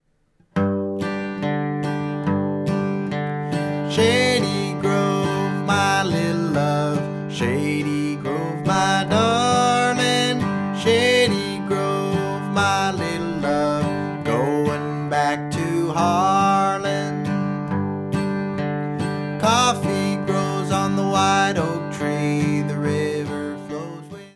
Guitar & voice, medium speed (key of G)